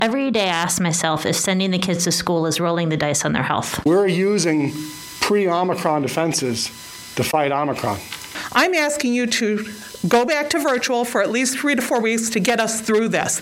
Right now, infection rates are setting record highs and parents and teachers told trustees they are concerned.